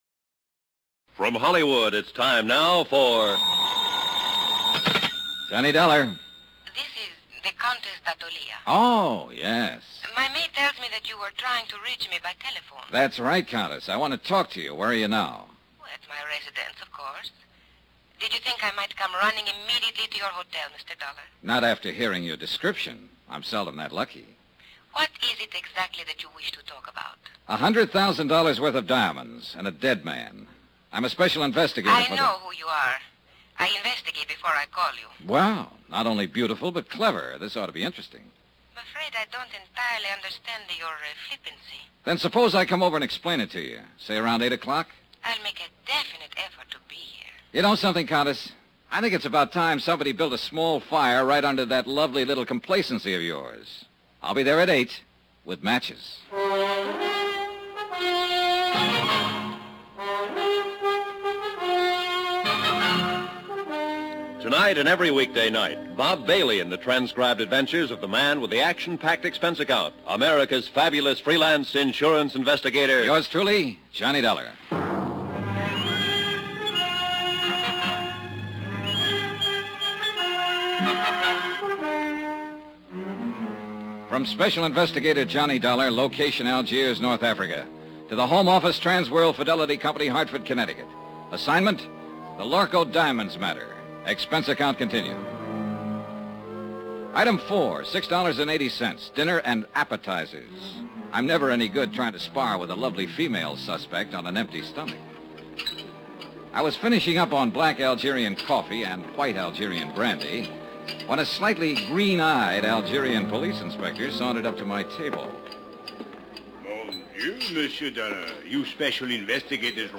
Yours Truly, Johnny Dollar Radio Program, Starring Bob Bailey